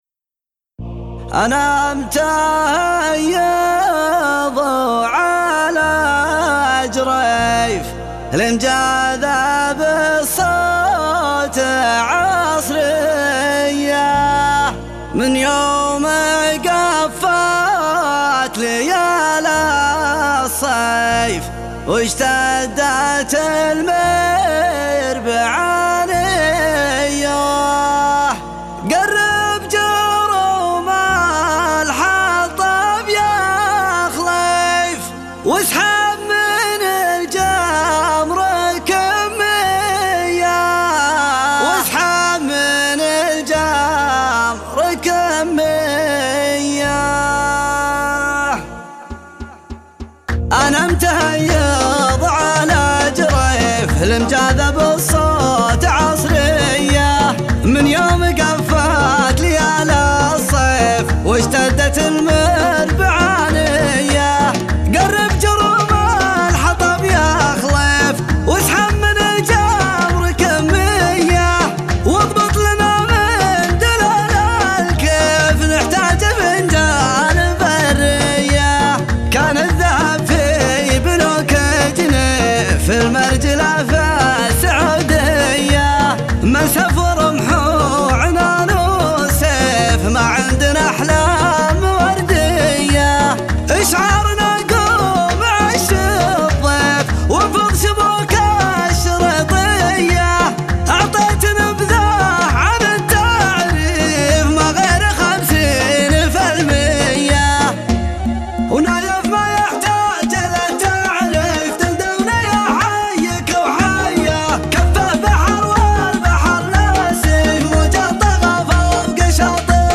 العذب – السامري.